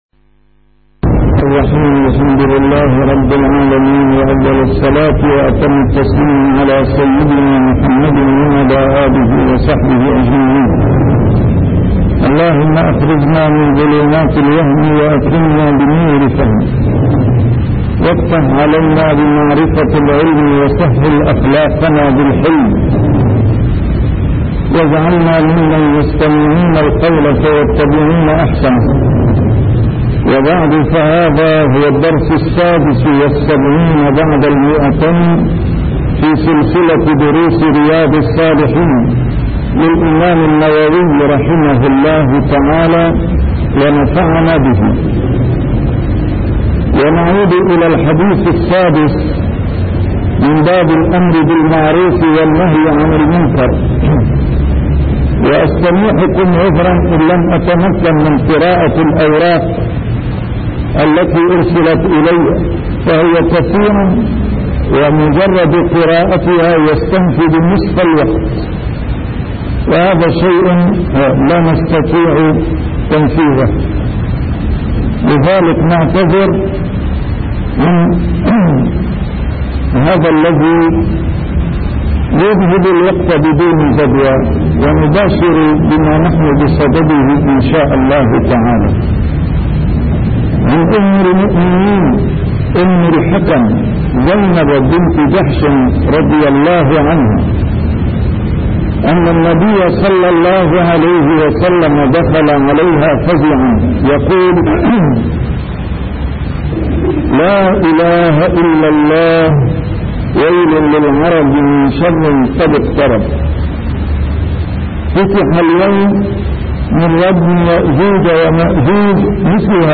A MARTYR SCHOLAR: IMAM MUHAMMAD SAEED RAMADAN AL-BOUTI - الدروس العلمية - شرح كتاب رياض الصالحين - 276- شرح رياض الصالحين: الأمر بالمعروف